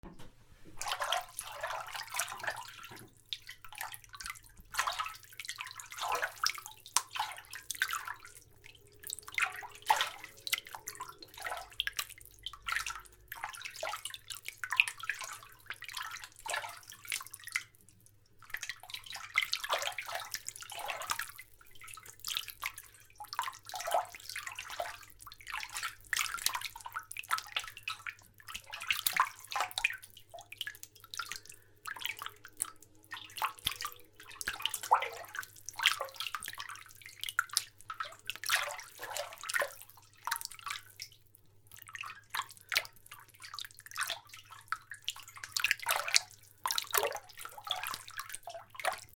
水音 水をかき回す やさしく
『チョロチョロ』